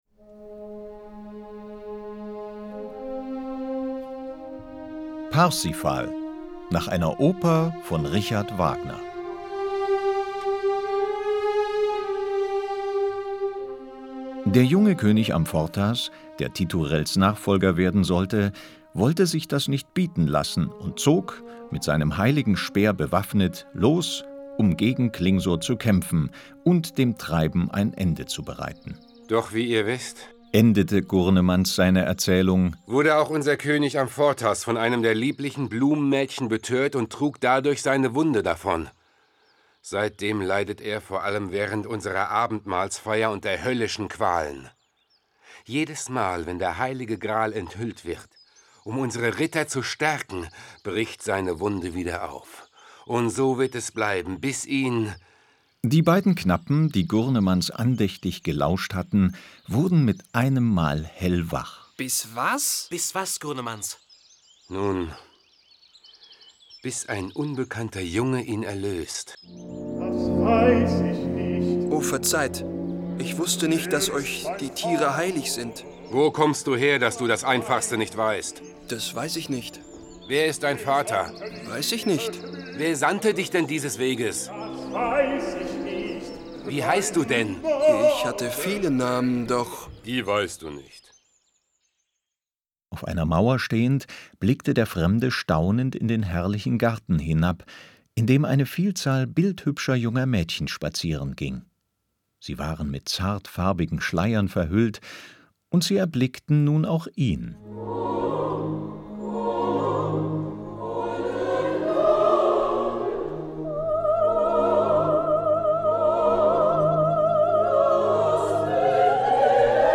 Hörspiel mit Opernmusik